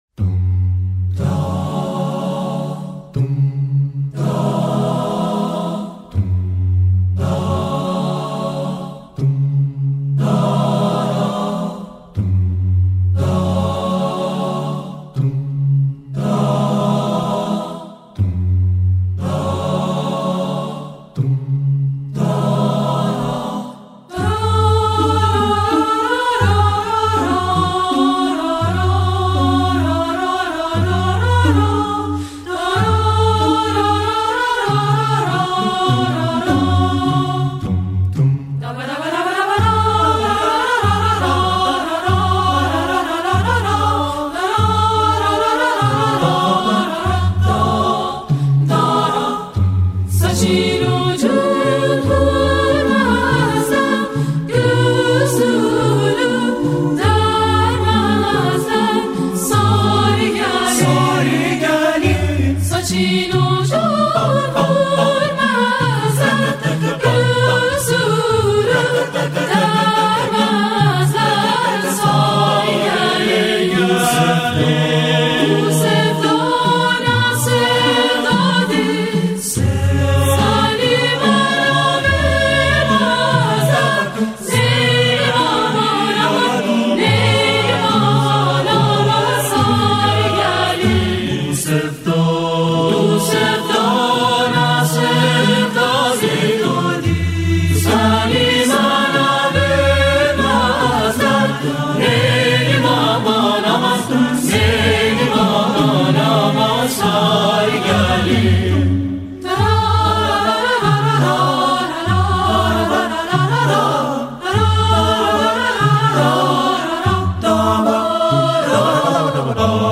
ژانر: پاپ & سنتی & راک